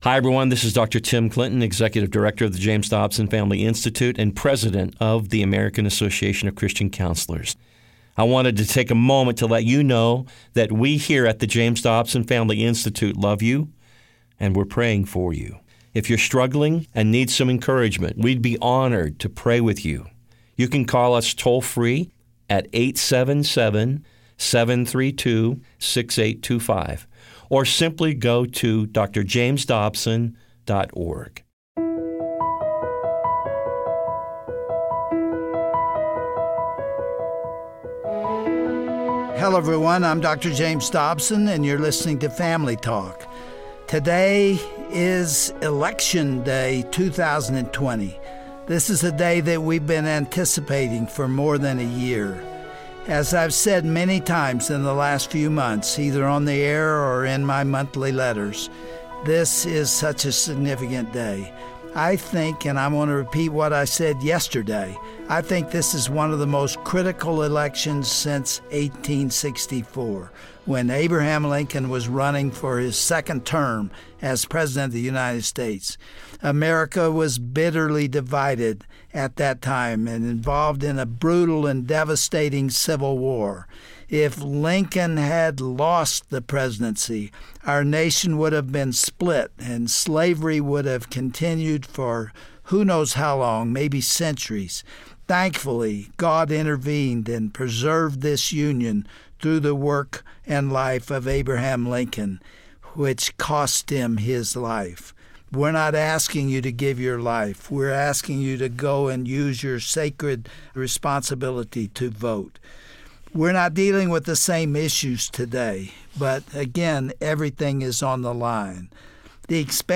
Host Dr. James Dobson Guest(s) Guest(s):Joel Rosenberg